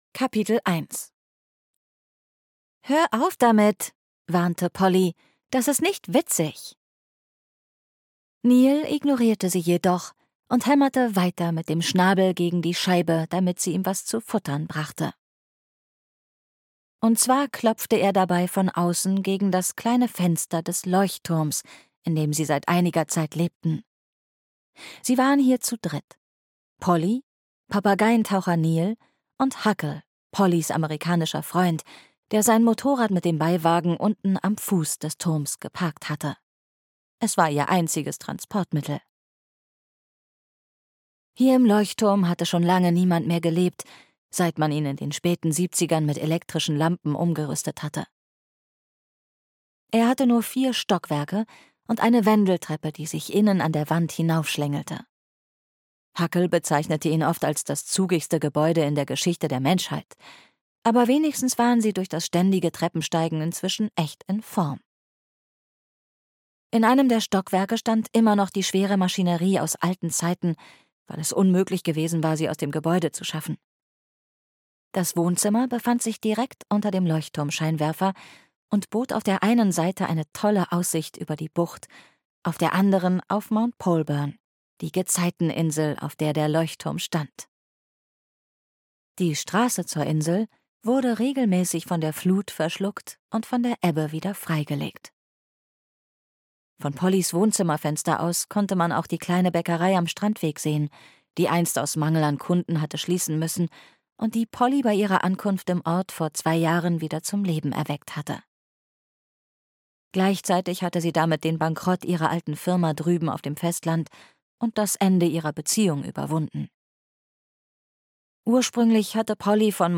Sommer in der kleinen Bäckerei am Strandweg (Die kleine Bäckerei am Strandweg 2) - Jenny Colgan - Hörbuch